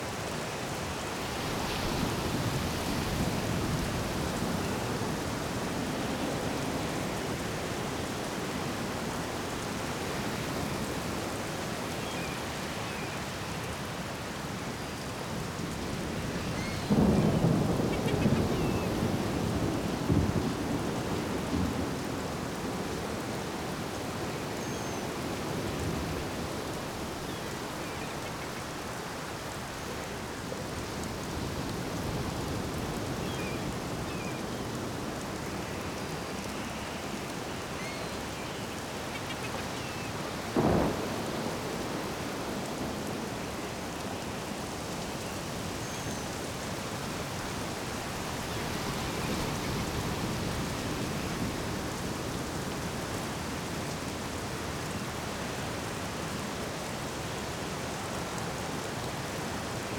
BGS Loops
Beach Storm.wav